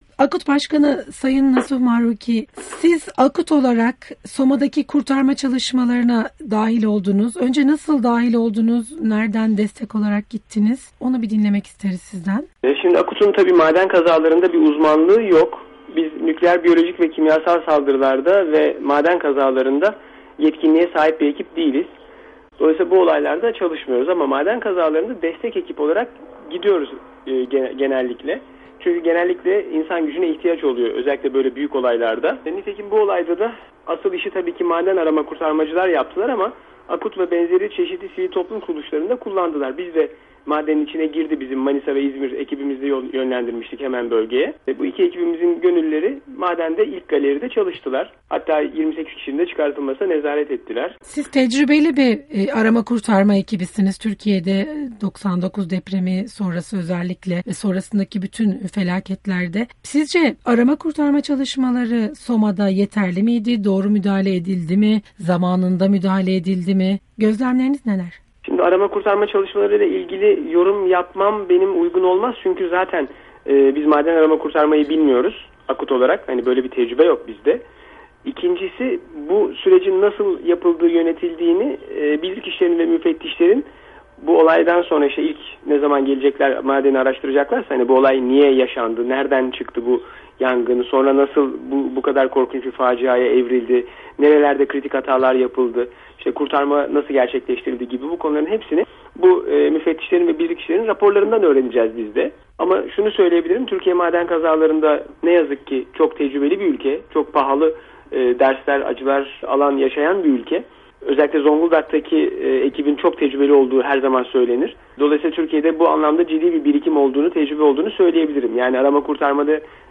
Bu soruların yanıtını, çalışmalara katılan arama kurtarma ekiplerinden AKUT’un başkan Nasuh Mahruki’ye sorduk.